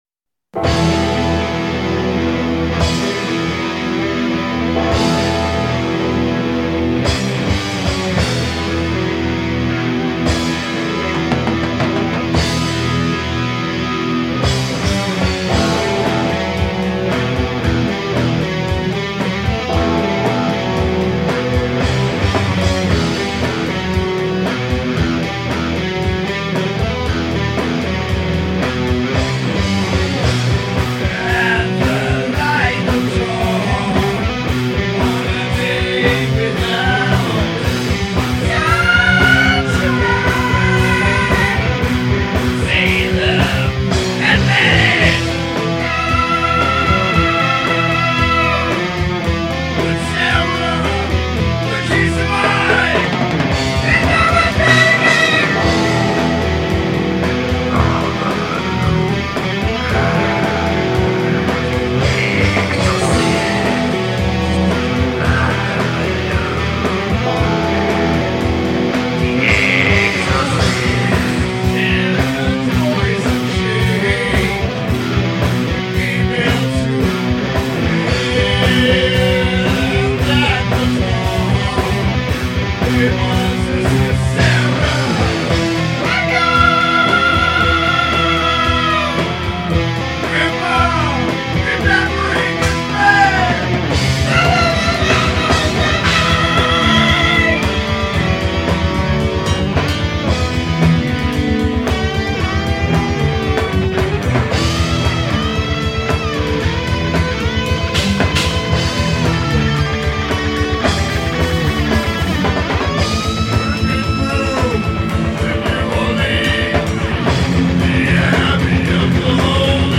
with Indianapolis' metal masters